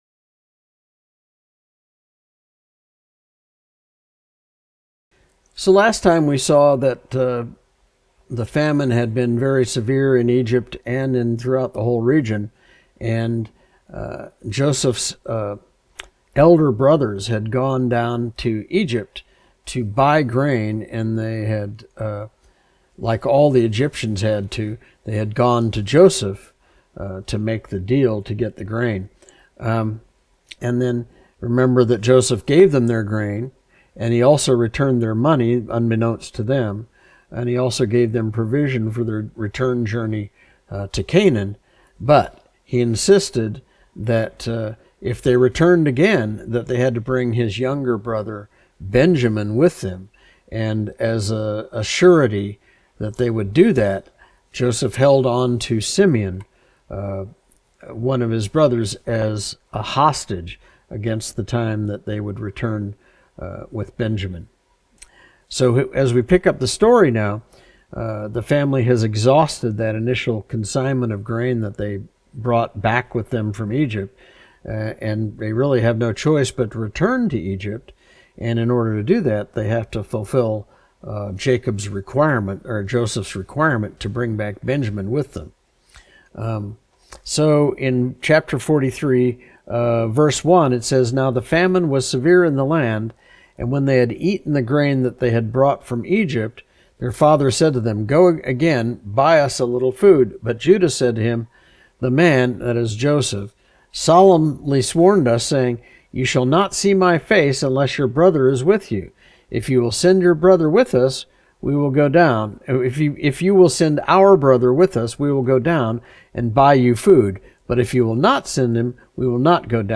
Study Type - Adult Lesson